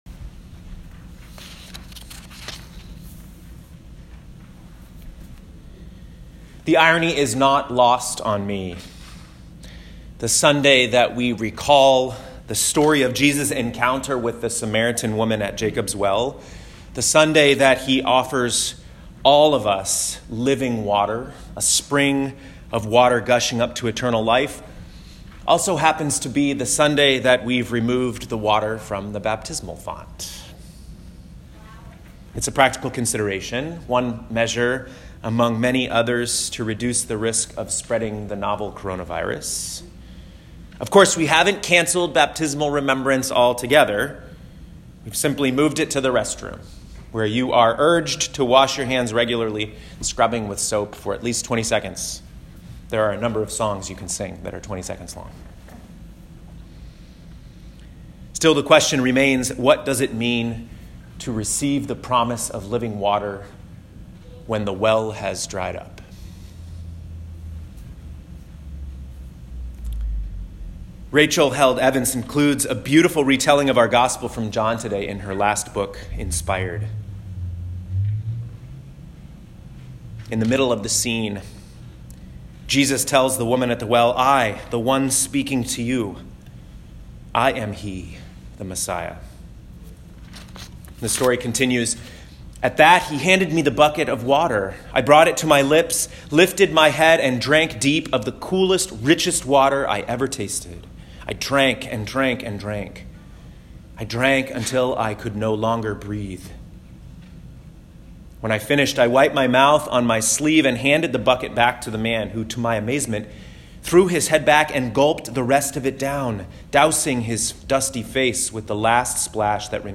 Third Sunday in Lent, Year A (3/15/2020) Exodus 17:1-7 Psalm 95 Romans 5:1-11 John 4:5-42 Click the play button to listen to this week’s sermon.